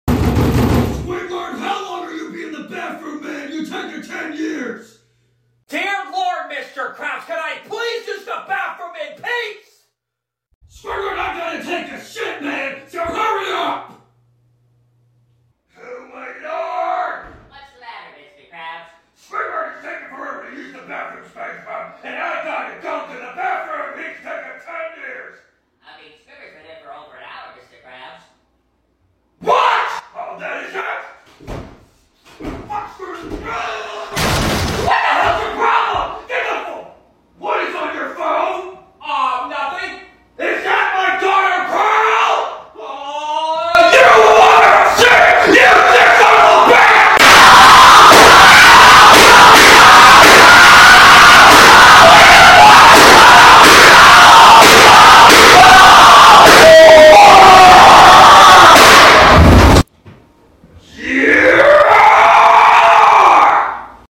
⚠WARNING EARRAPE⚠ Sound Effects Free Download